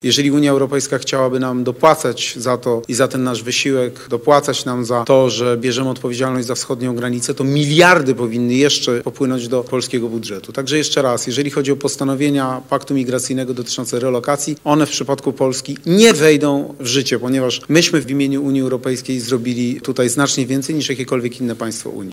– Nie będzie relokacji do Polski żadnych migrantów – zapewnił podczas konferencji w Ulhówku na Lubelszczyźnie kandydat Koalicji Obywatelskiej na prezydenta Polski Rafał Trzaskowski. W ten sposób odniósł się do przyjęcia przez Parlament Europejski paktu migracyjnego.